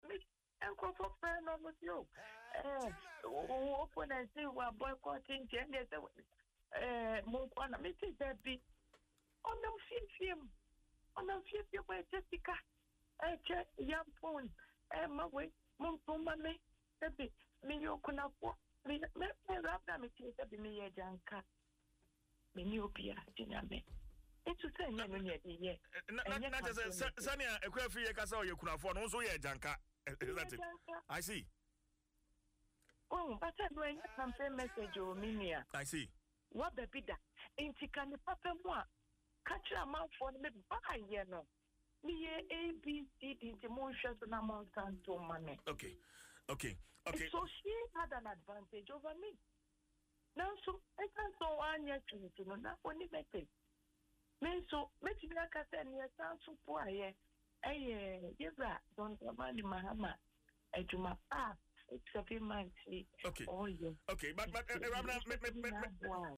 Speaking on Adom FM’s Dwaso Nsem after her narrow victory on Friday, July 11, 2025, Ms. Aubynn argued that her rival, a former MP, should have focused on her past achievements rather than appealing to emotion.